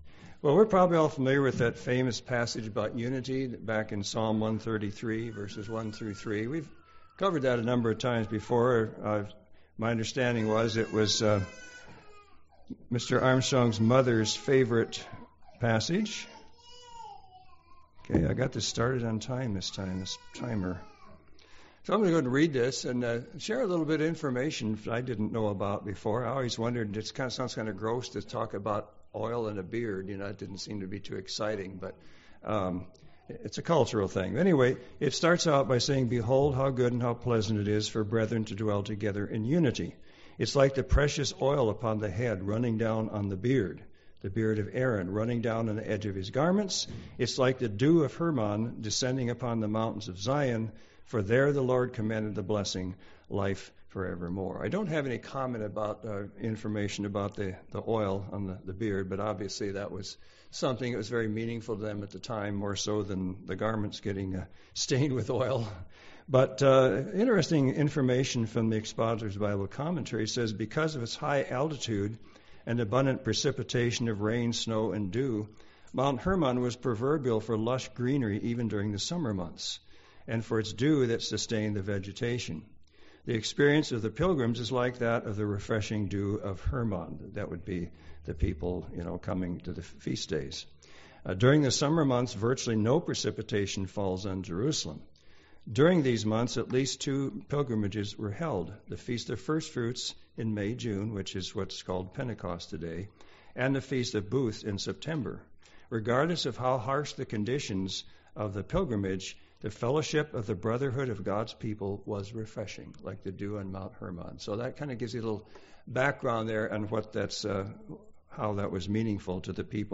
Given in Medford, OR